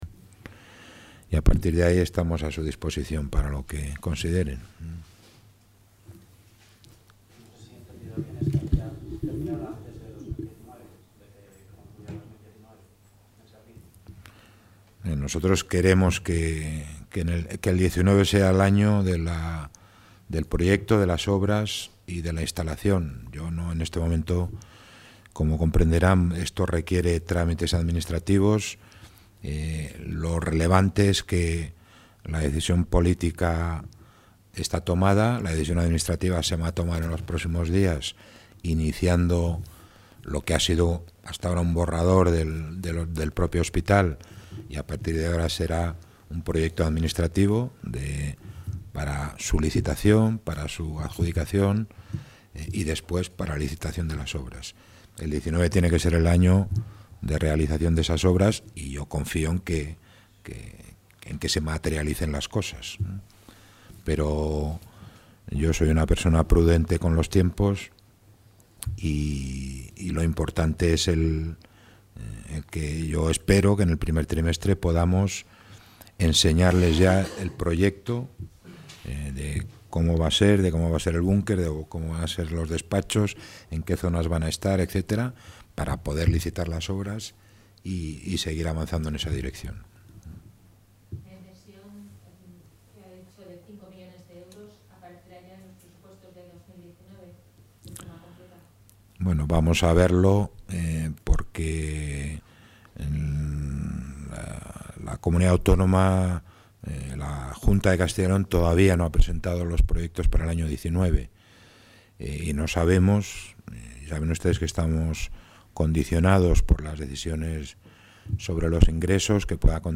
Intervención del consejero de Sanidad.
Intervención del consejero de Sanidad Atención a medios de comunicación El consejero de Sanidad informa sobre las unidades satélite de...